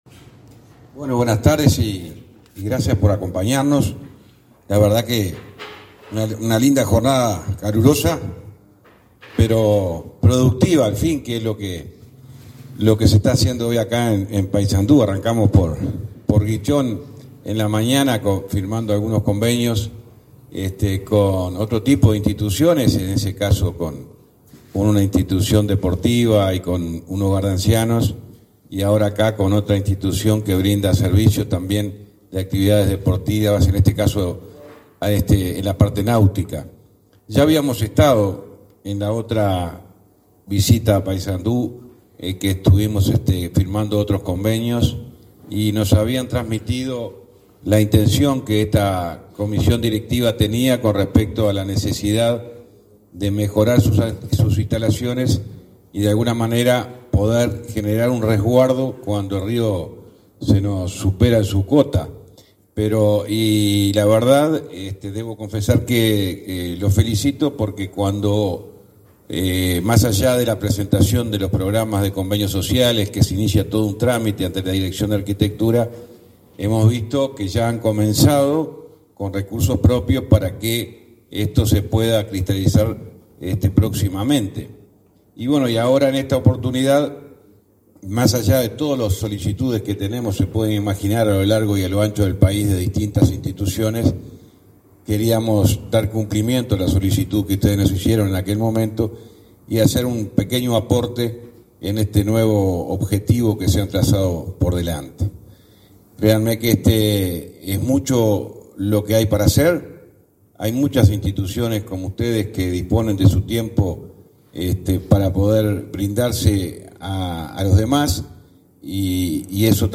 Palabras del ministro de Transporte y Obras Públicas, José Luis Falero
En el acto de acuerdo con el Yatch Club, Falero realizó declaraciones.